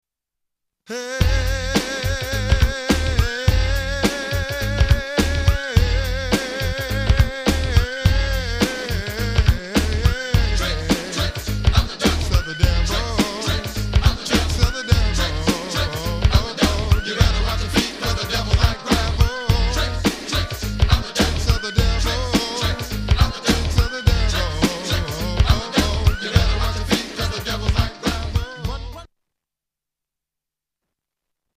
STYLE: Hip-Hop
locks into a tight groove with a catchy chorus